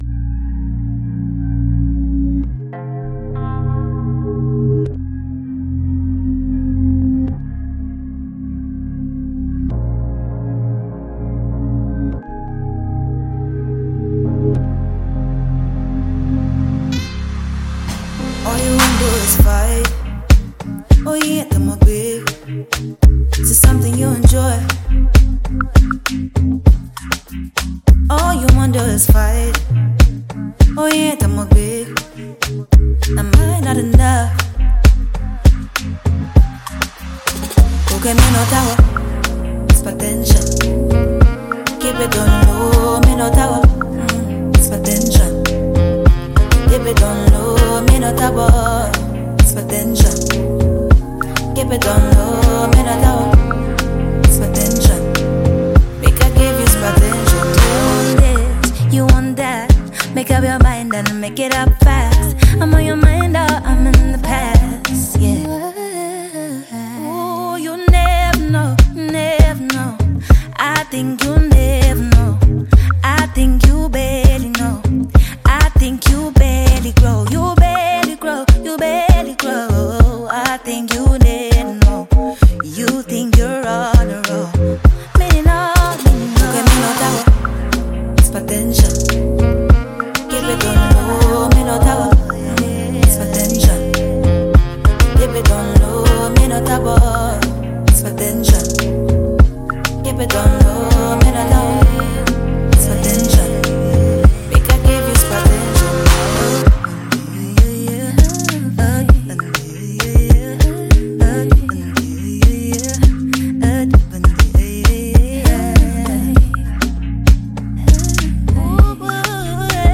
Ghana Music Music
Ghanaian celebrated female musician